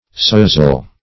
Sozzle \Soz"zle\, n.